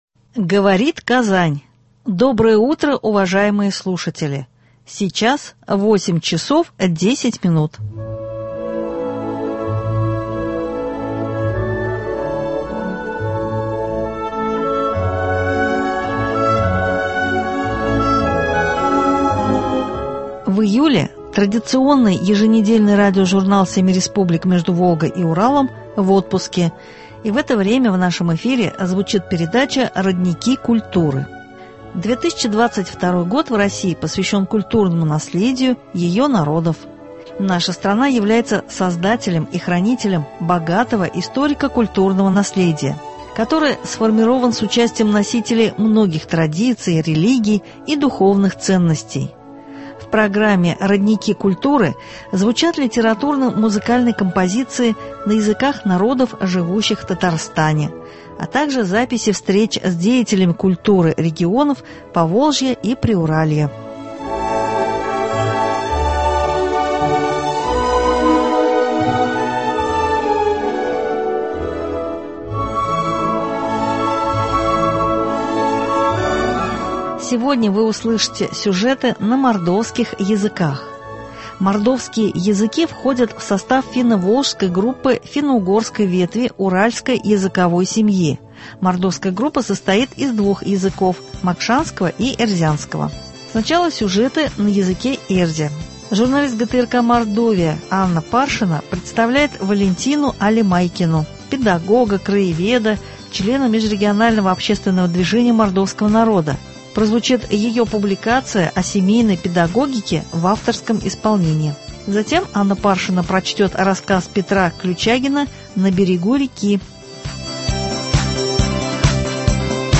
В программе «Родники культуры» звучат литературно — музыкальные композиции на языках народов, живущих в Татарстане, записи встреч с деятелями культуры регионов Поволжья и Приуралья.